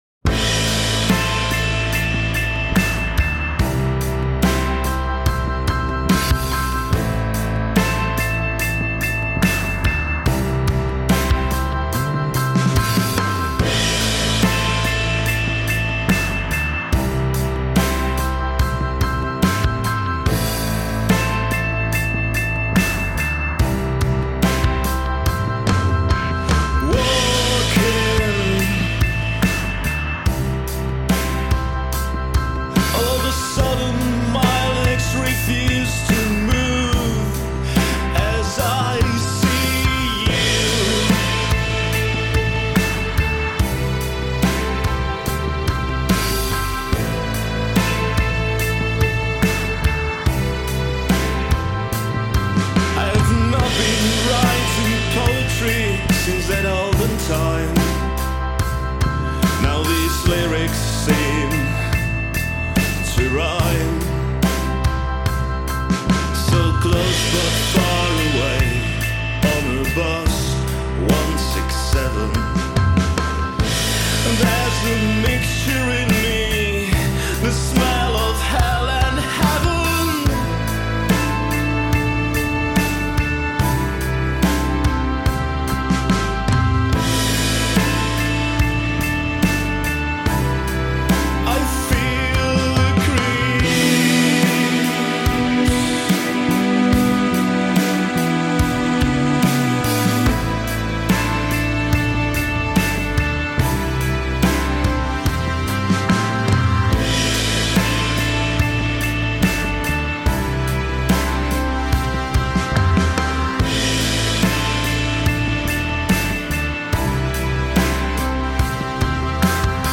Žánr: Indie/Alternativa
Nahráno leden až květen 2016, Praha & Beroun